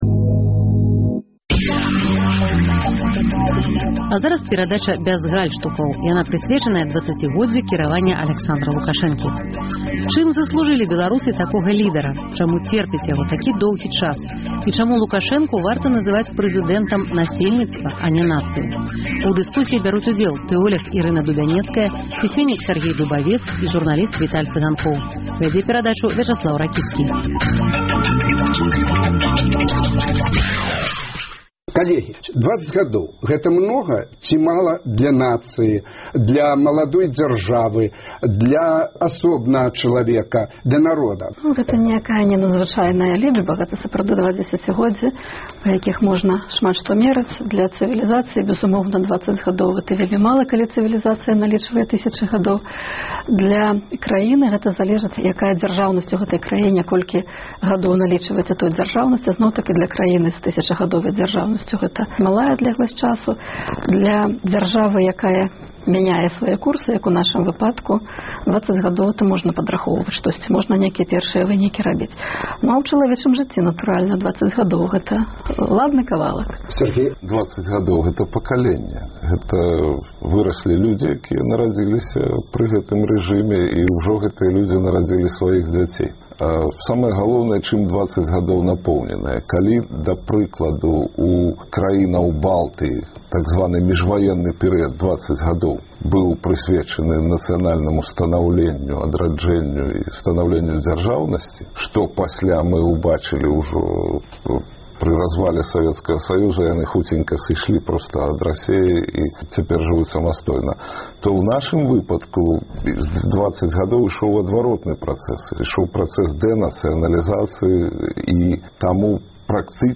Абмеркаваньне гарачых тэмаў у студыі Свабоды. Споўнілася 20 гадоў кіраваньню Аляксандра Лукашэнкі. Чым заслужылі беларусы такога лідара?